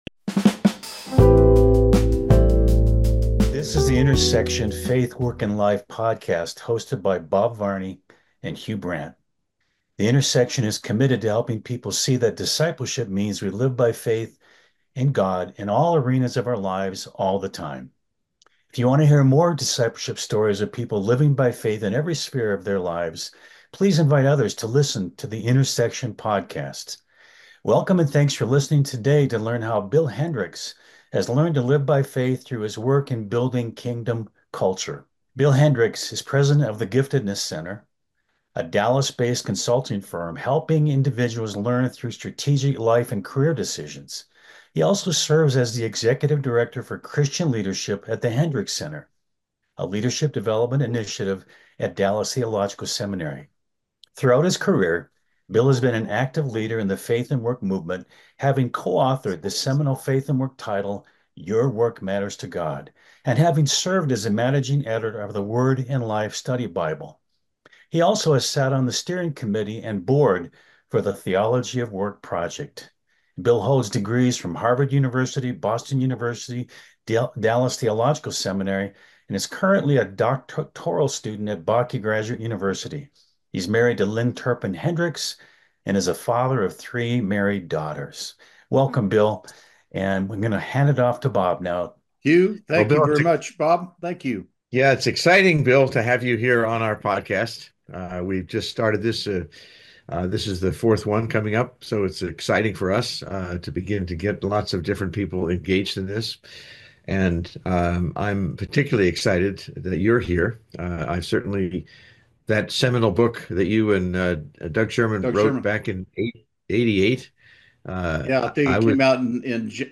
Tune in for an inspiring conversation with a true artist and friend!